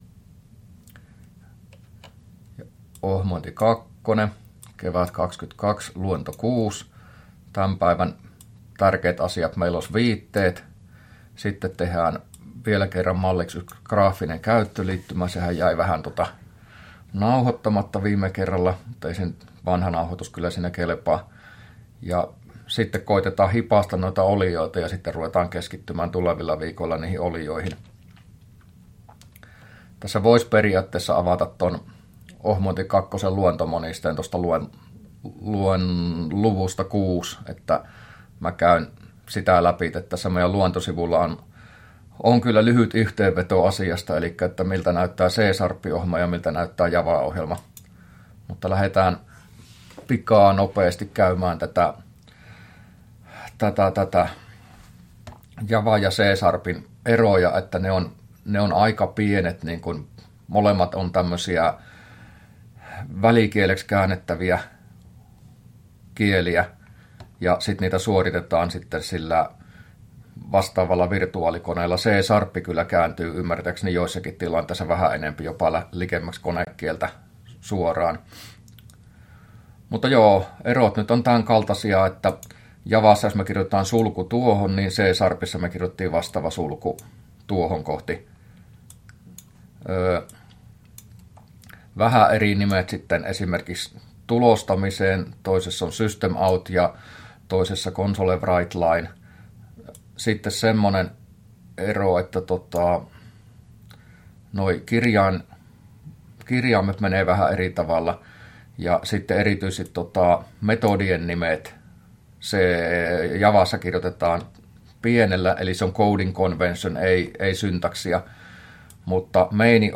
luento06a